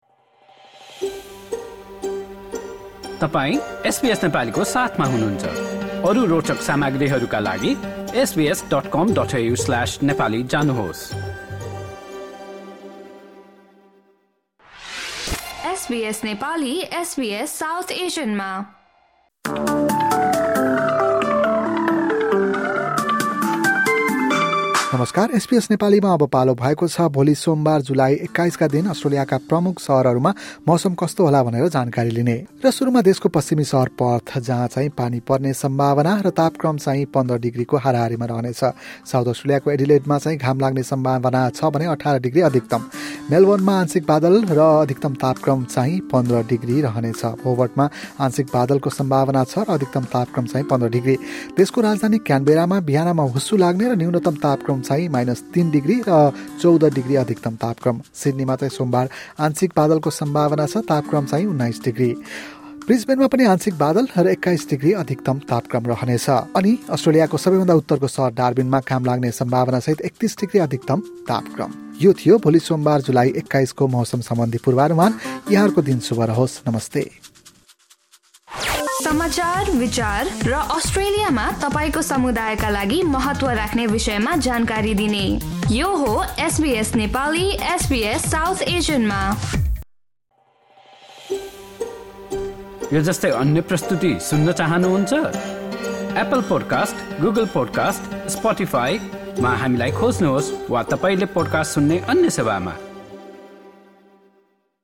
SBS Nepali Australian weather update: Monday, 21 July 2025